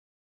silence_sm.wav